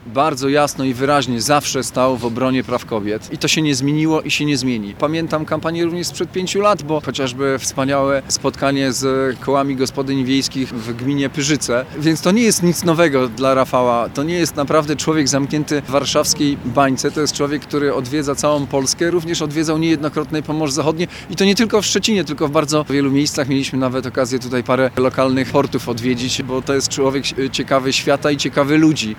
Olgerd Geblewicz, marszałek województwa zachodniopomorskiego i szef regionalnych struktur Platformy Obywatelskiej, podkreślał w swoich wypowiedziach, że Rafał Trzaskowski jest politykiem otwartym na dialog z różnorodnymi środowiskami społecznymi.